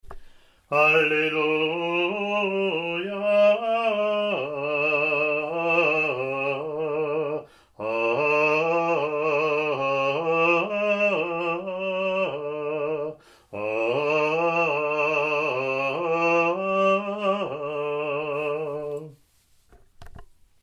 Alleluia Acclamation: Year A (2020),